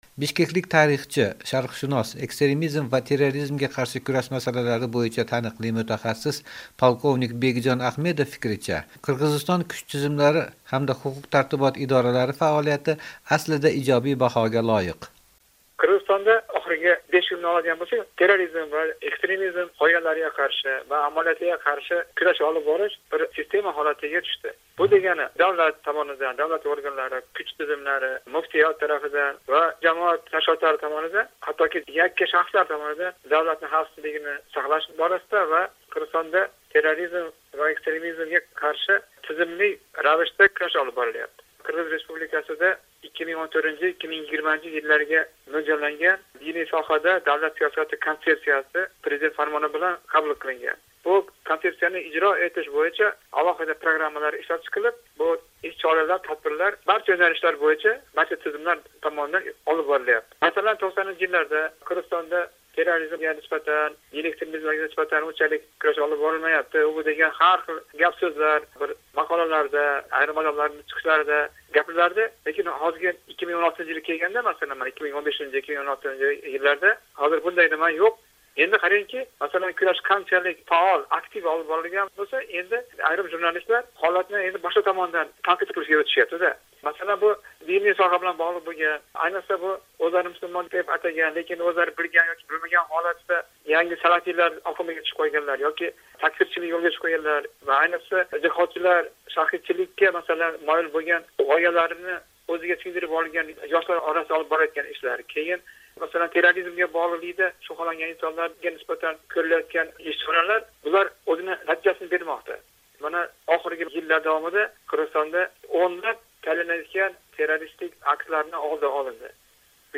Ekstremizmga qarshi kurash haqida ekspertlar bilan suhbat, Qirg'iziston